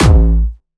Jumpstyle Kick 7
6 A#1.wav